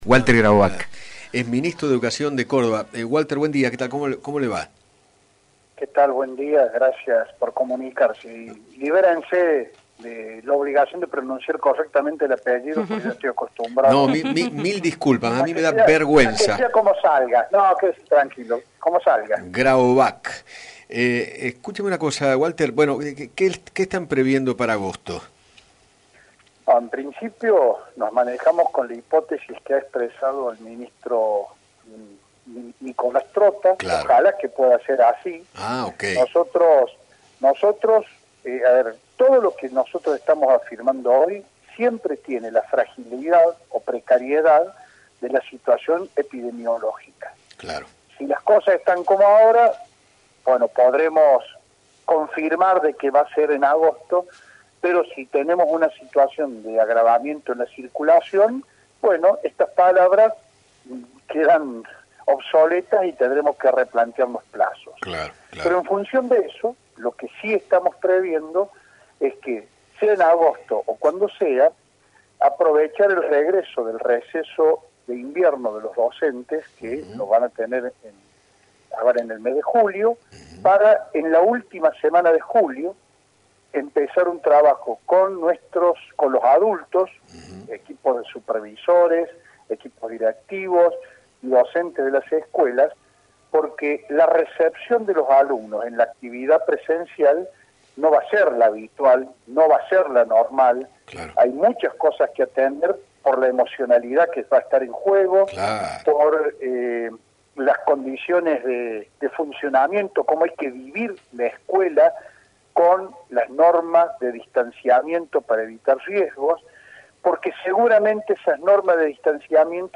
Walter Grahovac, Ministro de Educación de la Provincia de Córdoba, dialogó con Eduardo Feinmann sobre la posibilidad de que vuelvan las clases en agosto en esa Provincia y explicó cómo sería el protocolo tanto sanitario como psicológico para regresar al aula.